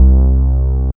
2408R BASS.wav